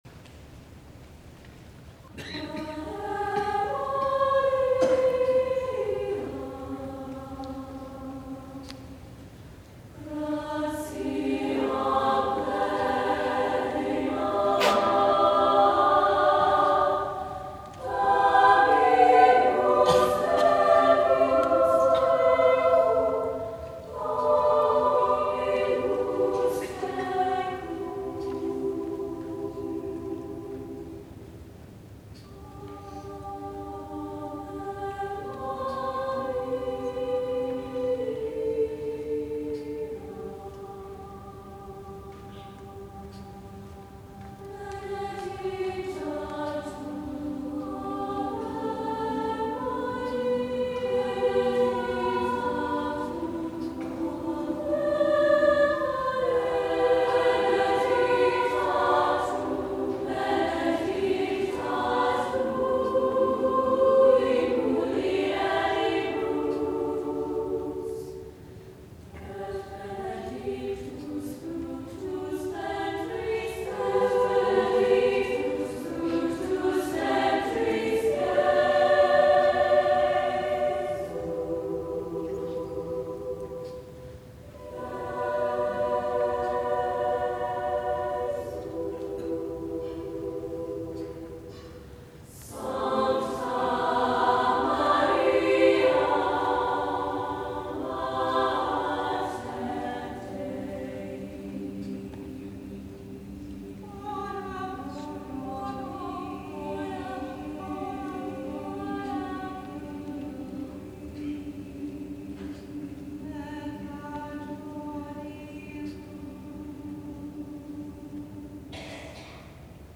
Voicing: SSAA a cappella
Decatur, Illinois